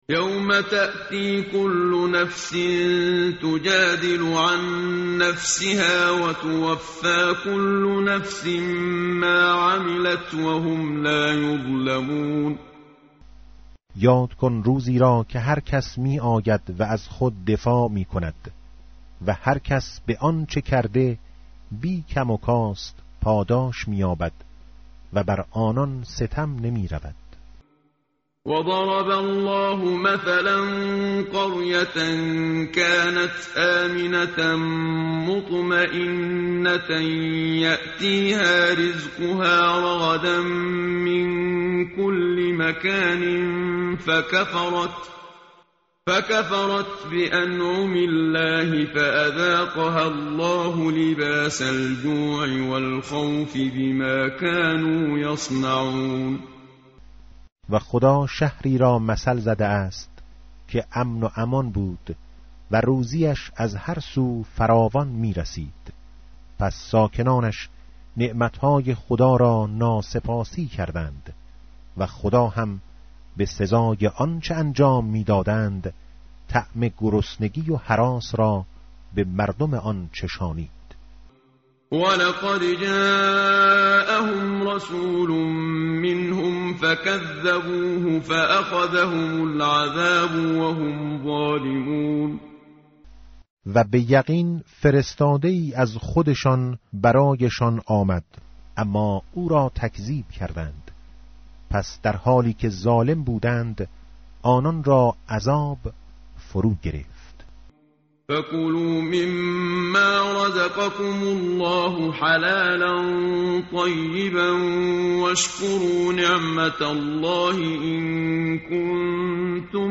متن قرآن همراه باتلاوت قرآن و ترجمه
tartil_menshavi va tarjome_Page_280.mp3